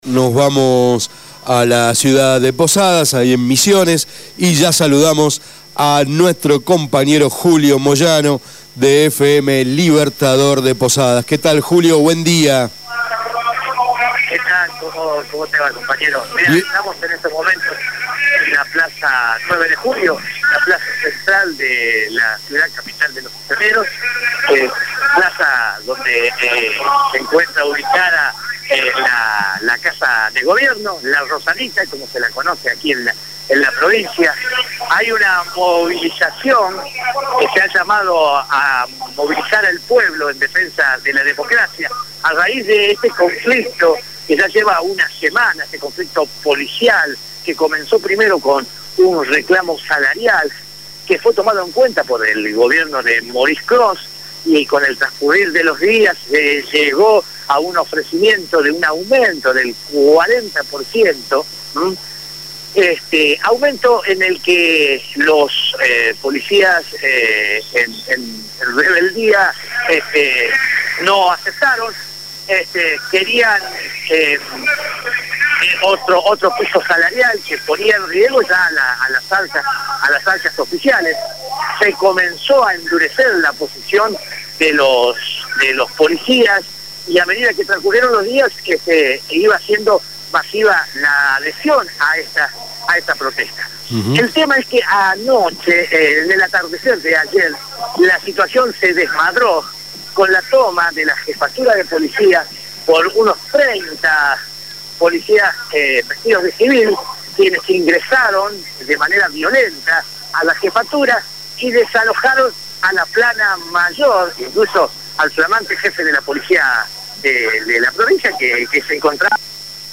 Informe sobre la huelga de policías en Misiones